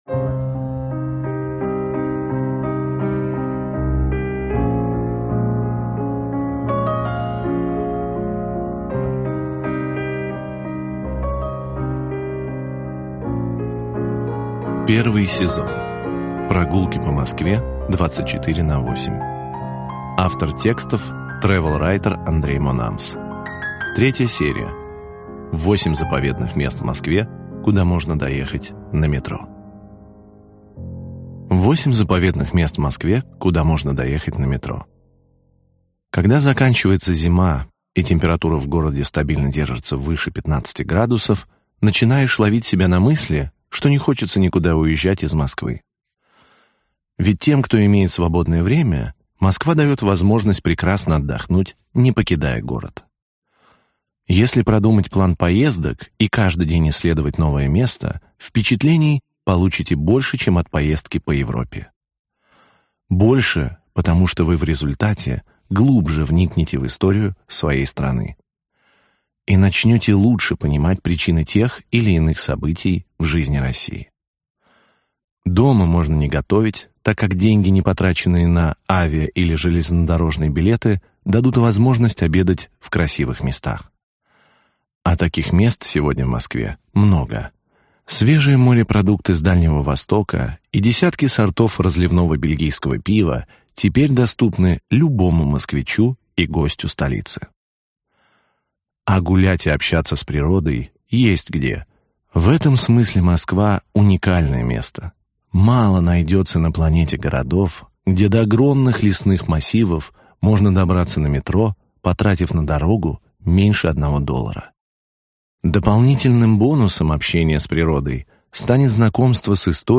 Аудиокнига 8 заповедных мест в Москве, куда можно доехать на метро. Глава 1. Коломенское – западная резиденция царей | Библиотека аудиокниг